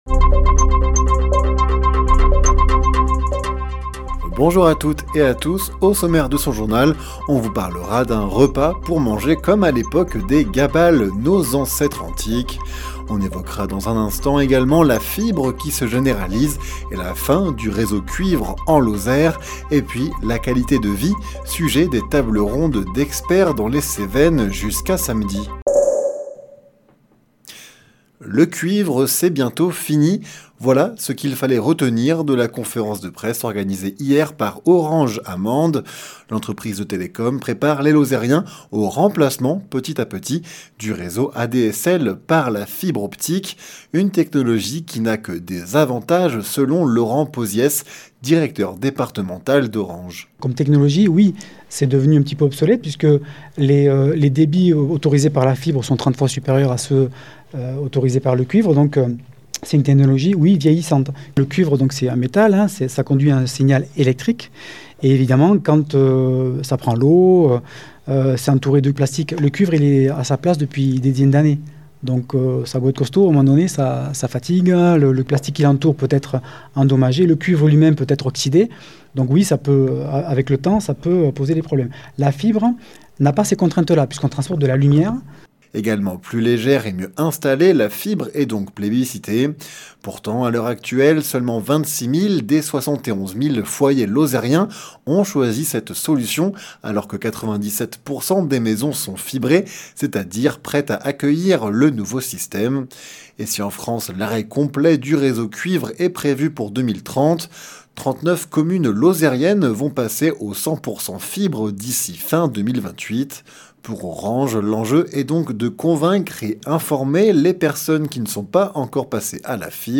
Les informations locales
Le journal sur 48FM présenté par